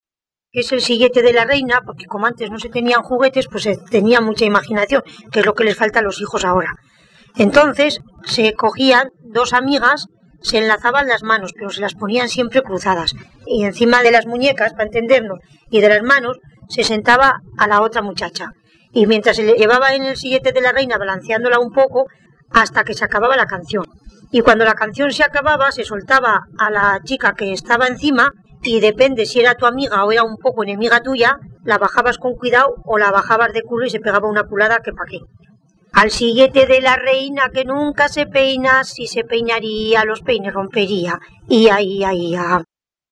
Clasificación: Cancionero
Lugar y fecha de recogida: Calahorra, 12 de enero de 2003
Se trata de una canción infantil y femenina en apariencia intrascendente.